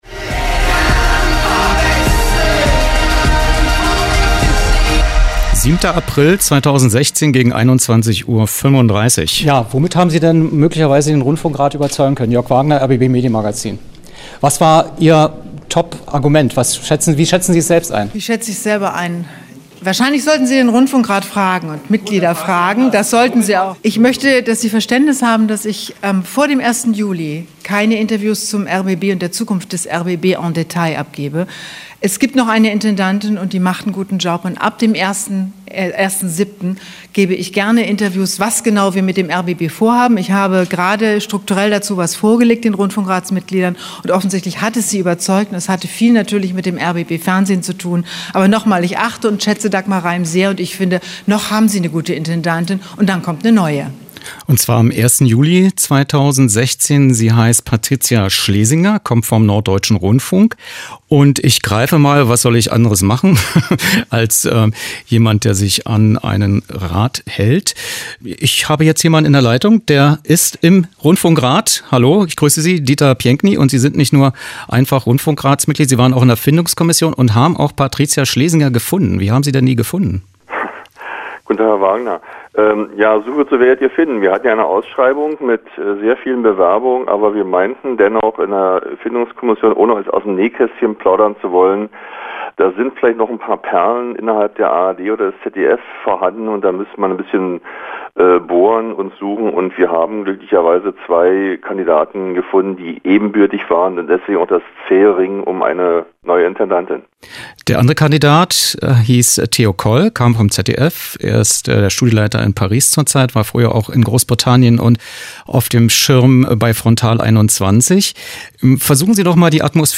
Was: Bericht von der rbb-Intendantinwahl am 07.04.2016
* Patricia Schlesinger, zur Zeit Leiterin des Programmbereichs Kultur und Dokumentation des NDR-Fernsehens
Wo: Potsdam, Medienstadt, rbb-Fernsehhaus, Foyer